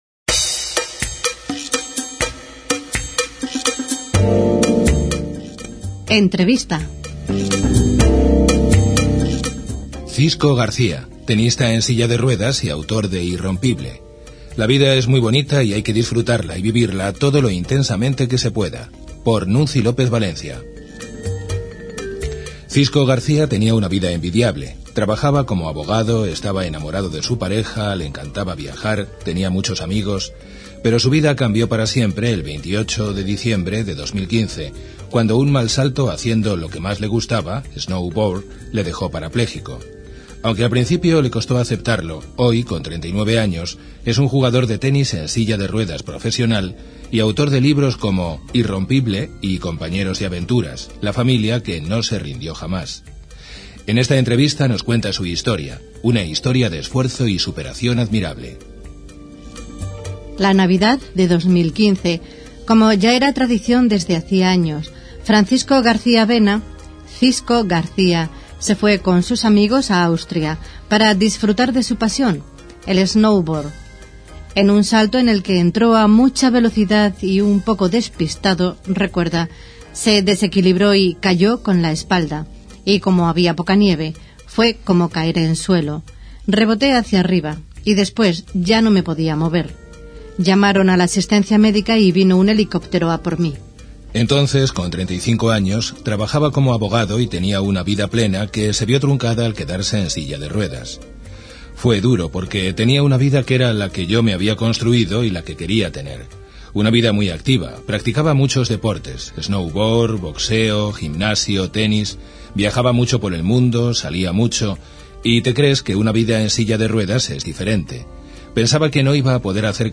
06 ENTREVISTA_24.mp3